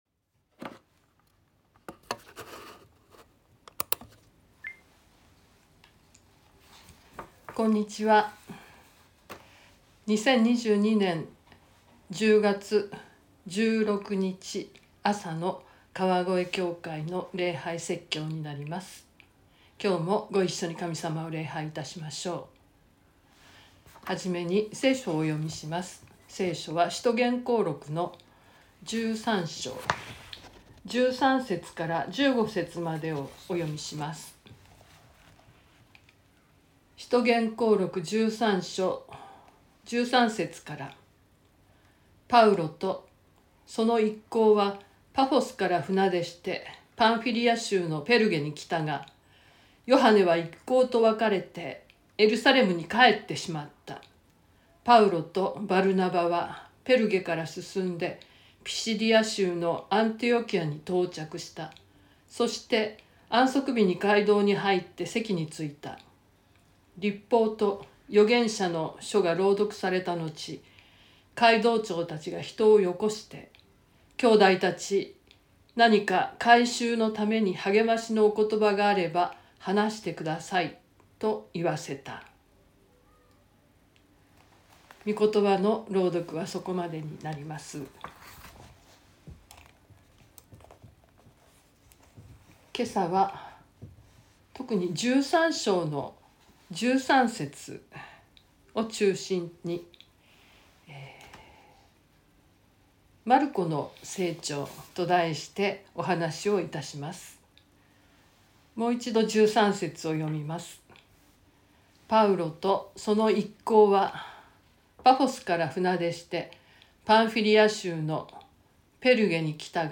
2022年10月16日朝の礼拝「マルコの成長」川越教会
音声ファイル 礼拝説教を録音した音声ファイルを公開しています。